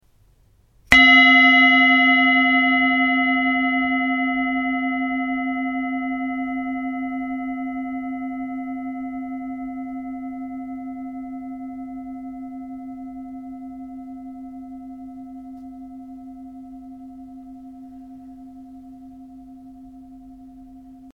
Tibetische Klangschale - HERZSCHALE
Grundton: 263,88 Hz
1. Oberton: 705,41 Hz
KM72D-598g Planeten Schale.mp3